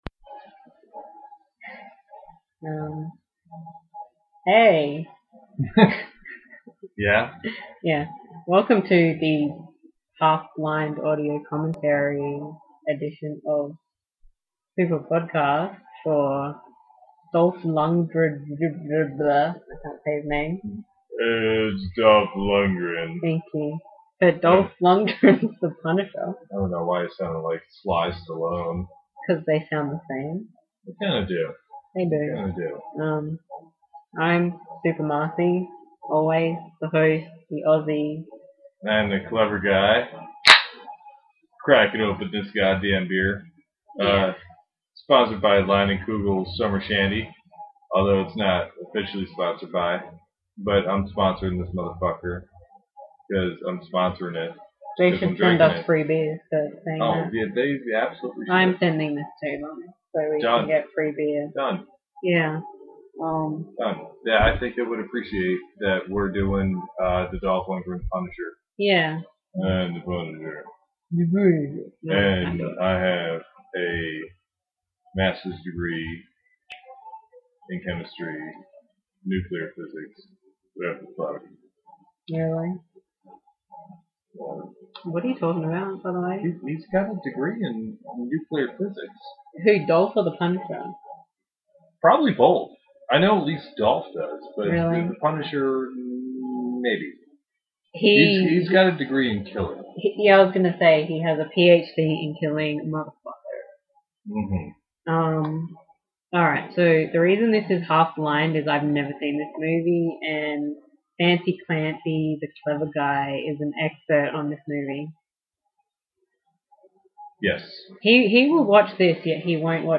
You simply need to grab a copy of the film, and sync up the podcast audio with the film.
punishercommentary.mp3